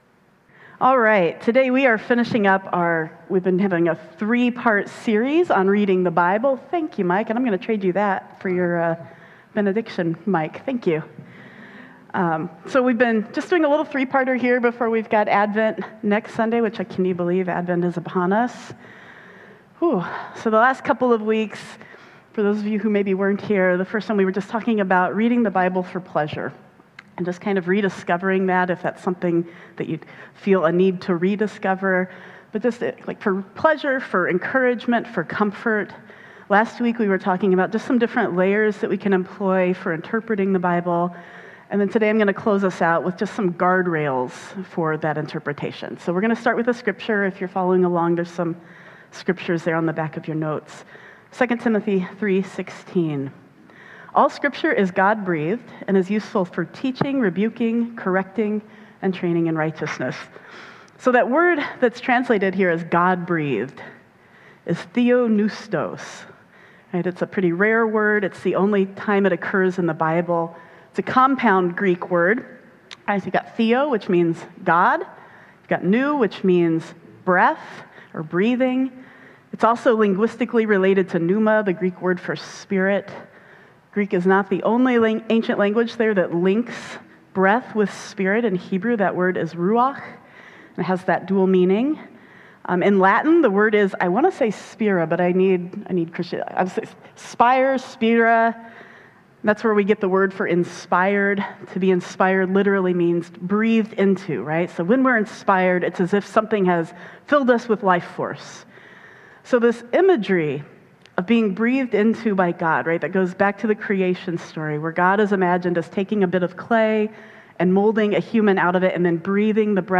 Blue Ocean Church Ann Arbor Sunday Sermons
An audio version of our weekly Sunday messages.